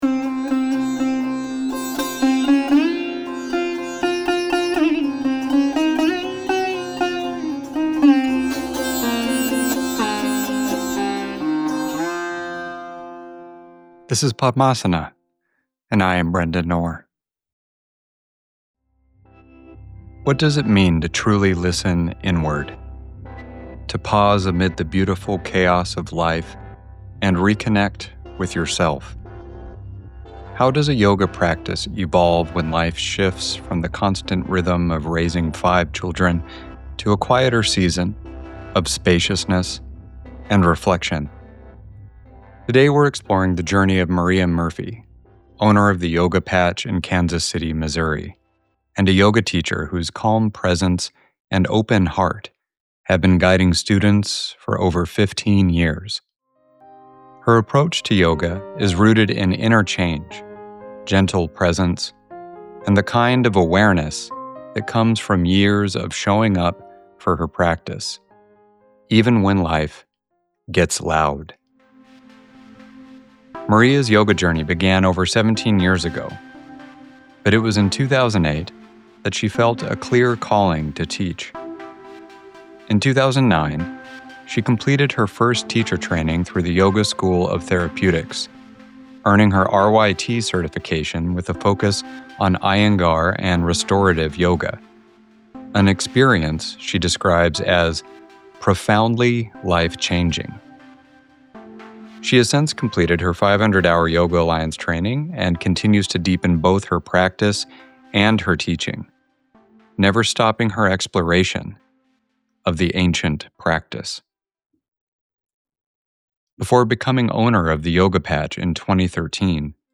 A gentle conversation about accessible yoga, motherhood's wisdom, and permission to pause.